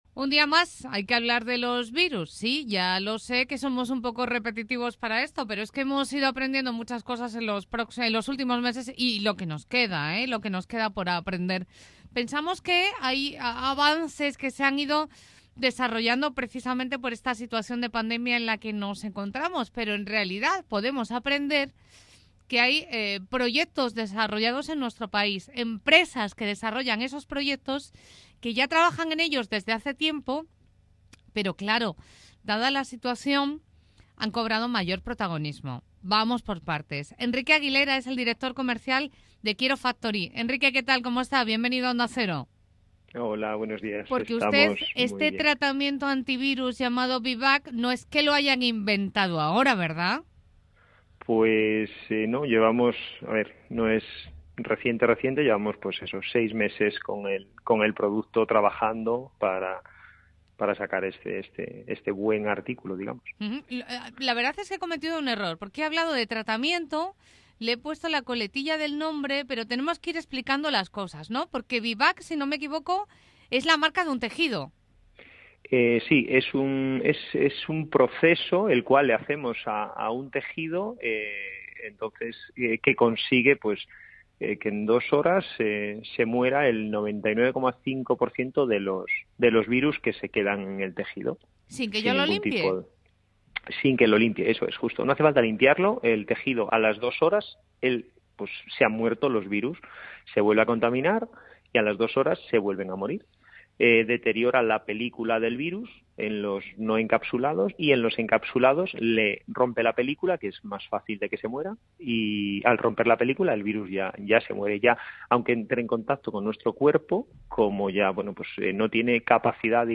Entrevistas VIBAAC – Kierofactory
Entrevisa-virus.mp3